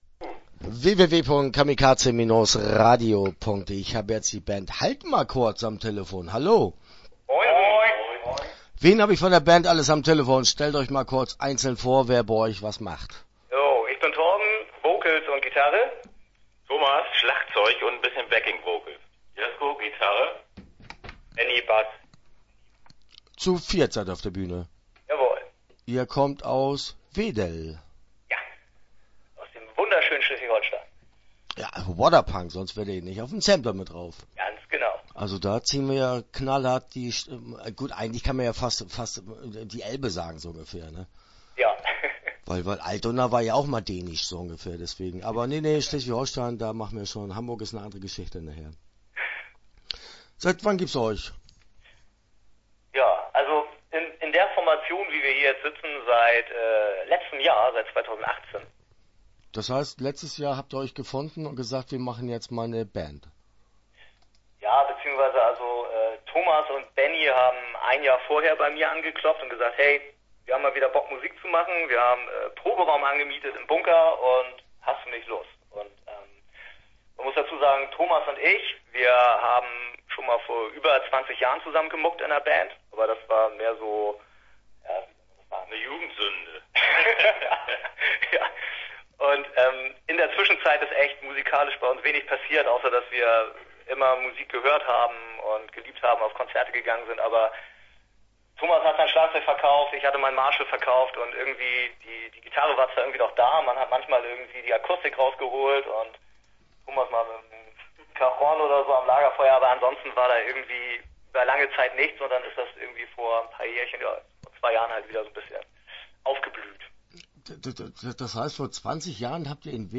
Haltmalkurz - Interview Teil 1 (10:30)